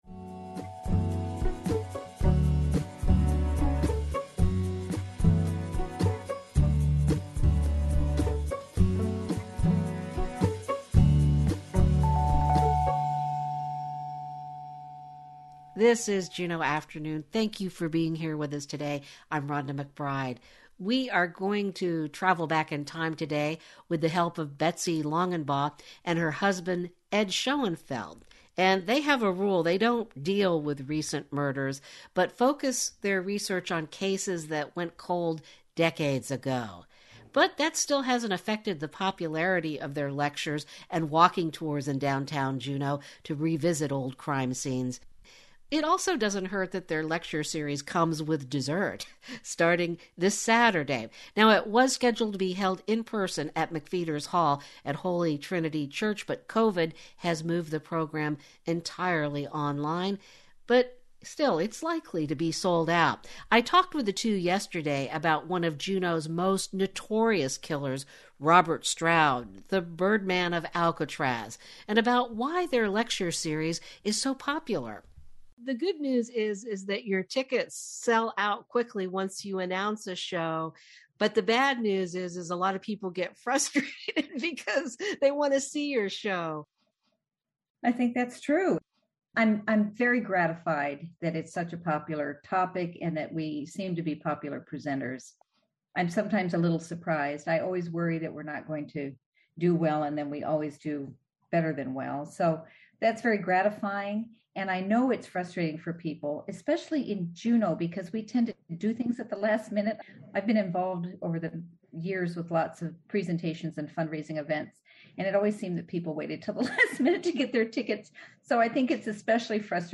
talks with a group of Tongans about how they are coping as they wait and hope for news from home.